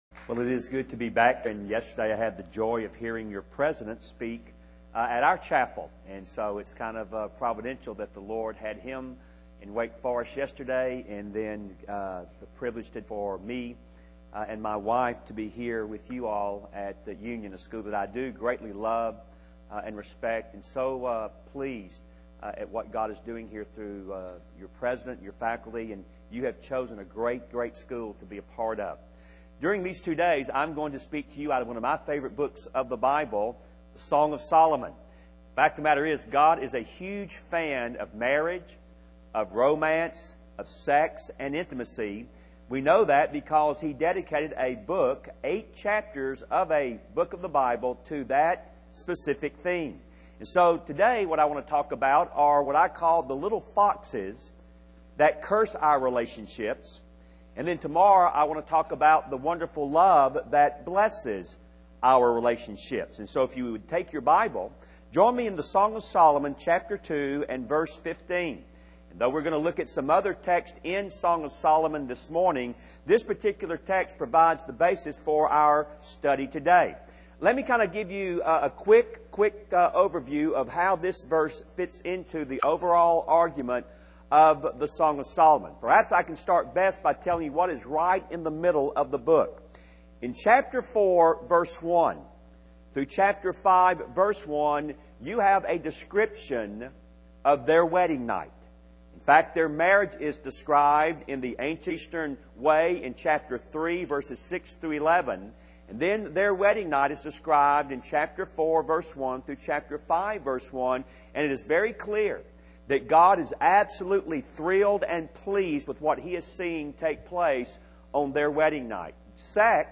Family Life Series: Crabtree Lectures Session 1